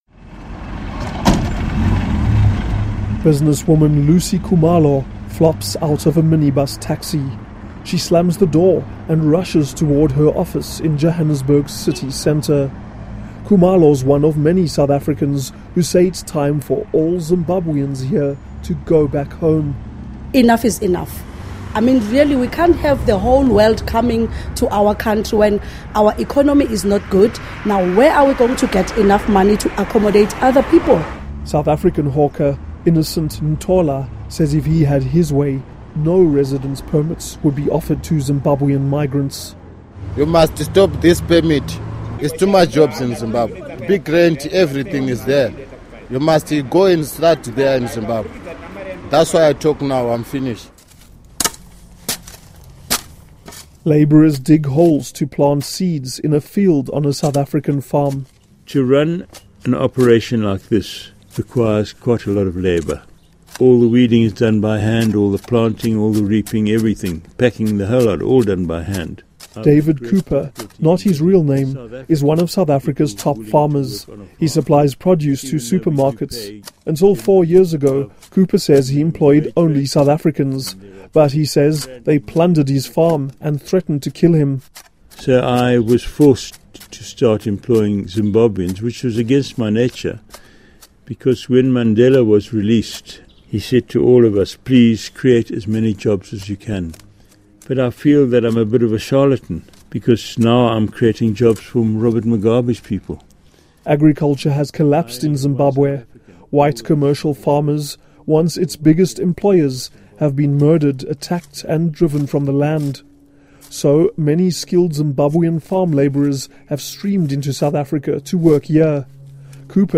Report on Zimbabwean expulsions from South Africa